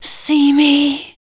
WHISPER 06.wav